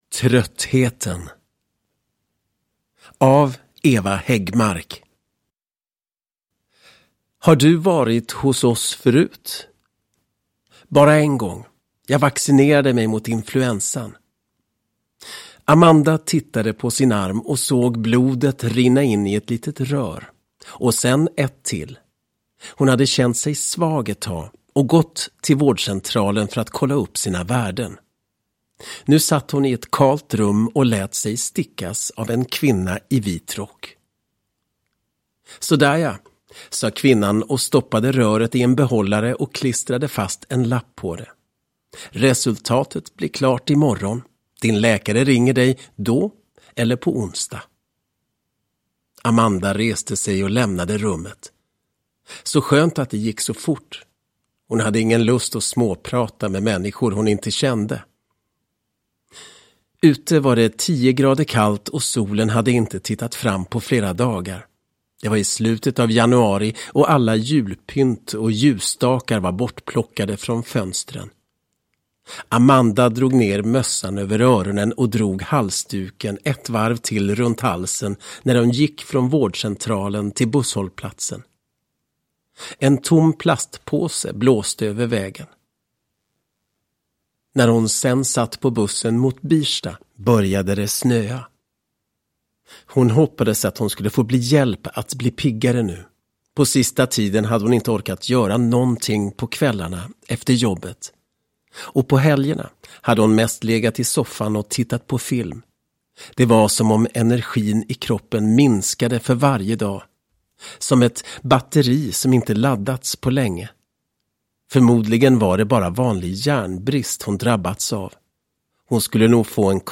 Tröttheten: Mörkret utanför del 1 – Ljudbok – Laddas ner